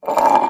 glass_m1.wav